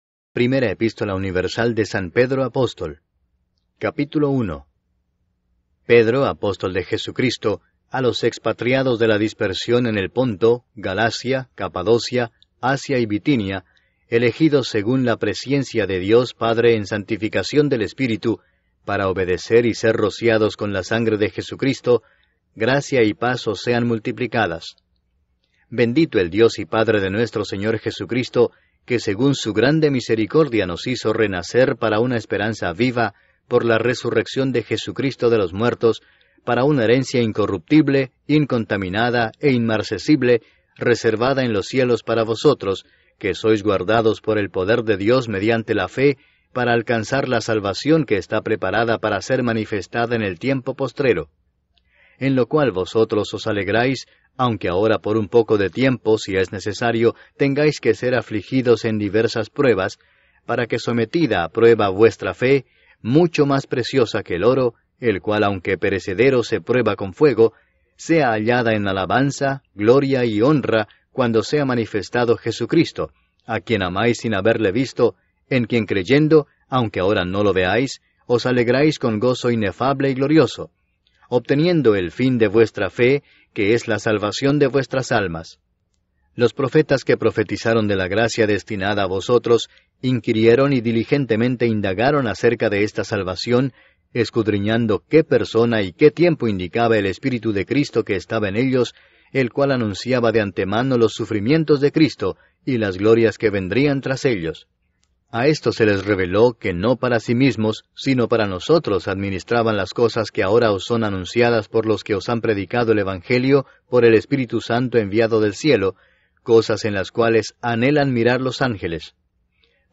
Apocalipsis completo narrado: esperanza, juicio y fidelidad en medio del fin